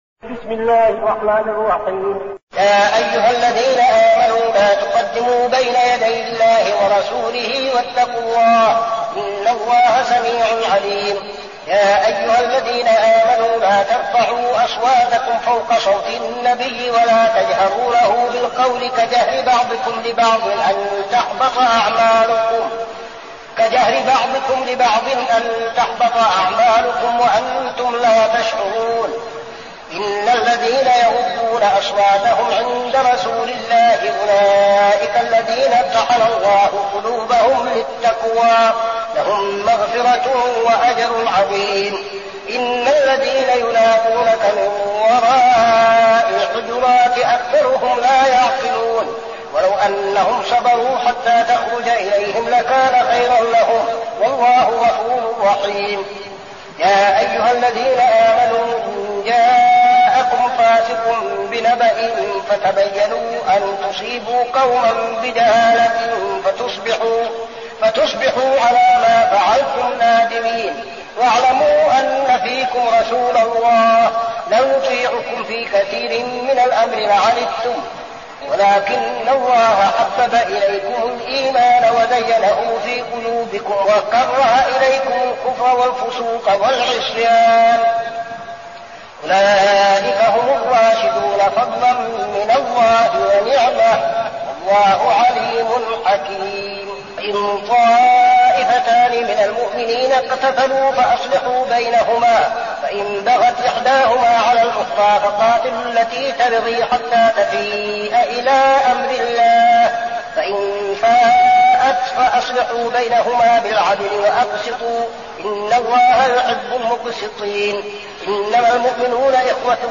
المكان: المسجد النبوي الشيخ: فضيلة الشيخ عبدالعزيز بن صالح فضيلة الشيخ عبدالعزيز بن صالح الحجرات